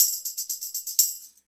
Index of /90_sSampleCDs/Roland L-CD701/PRC_Latin 2/PRC_Tambourines
PRC TAMB LIK.wav